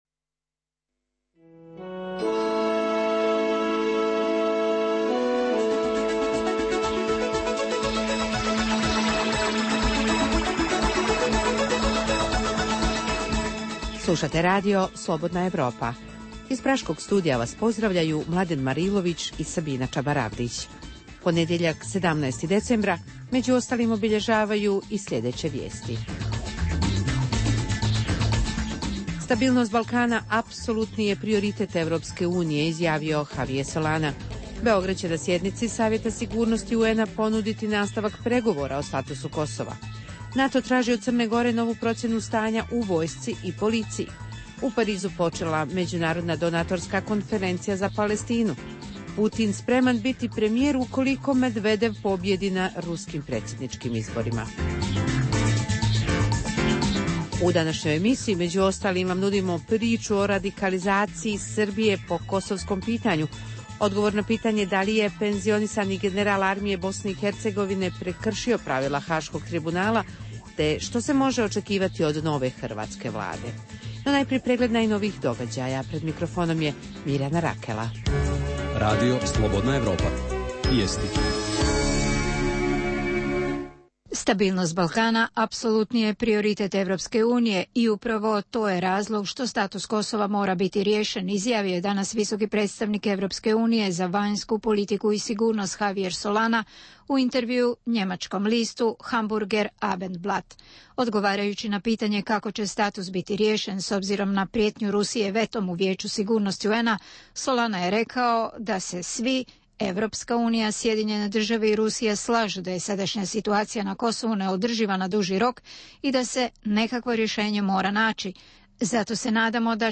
U regionalnom izdanju programa Radija Slobodna Evropa danas govorimo o radikalizaciji Srbije na kosovskom pitanju. Tražimo i odgovore na pitanja da li je penzionisani general Armije BiH Rasim Delić prekršio pravila Haškog tribunala te što se može očekivati od nove hrvatske vlade. U Dokumentima dana možete poslušati interview s Nikolom Špirićem, analizu raslojavanja vodećih političkih stranaka u BiH, te temu o tome zašto desne stranke u Srbiji nude Rusiji prostor za trajne vojne baze.